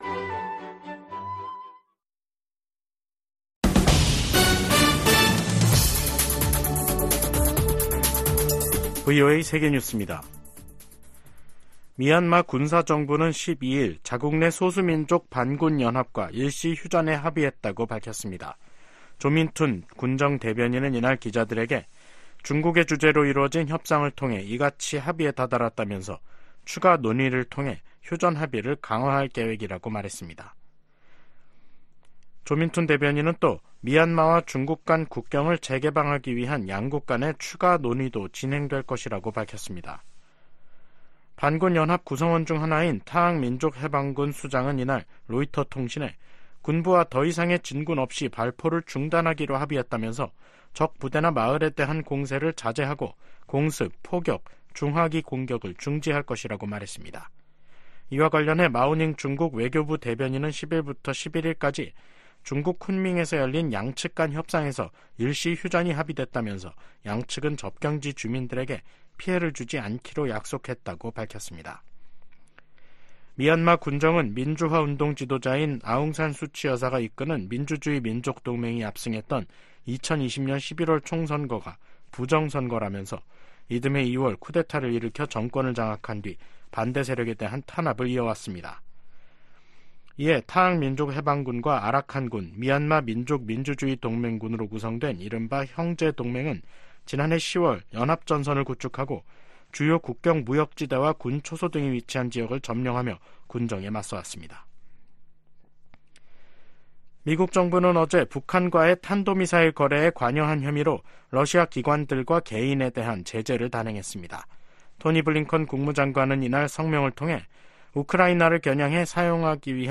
VOA 한국어 간판 뉴스 프로그램 '뉴스 투데이', 2024년 1월 12일 3부 방송입니다. 미국이 북한 탄도미사일의 러시아 이전과 시험에 관여한 러시아 기관과 개인에 제재를 가했습니다. 미 국무부는 북한제 미사일 사용 증거가 없다는 러시아 주장을 일축했습니다. 국제 인권단체 휴먼라이츠워치는 '2024 세계 보고서'에서 북한 정부가 지난해에도 코로나 방역을 핑계로 계속 주민들의 기본권을 침해했다고 비판했습니다.